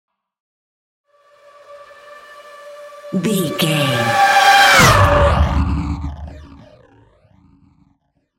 Sci fi vehicle whoosh large
Sound Effects
futuristic
intense
whoosh